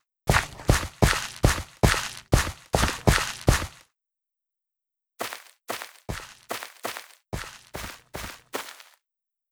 Rooted dirt footstep sounds are considerably quieter than normal dirt footstep sounds
3. Notice that the regular dirt sounds a lot louder than the rooted dirt.
• Audio recording of footstep sounds (enhanced for comparison)
dirtsounds.wav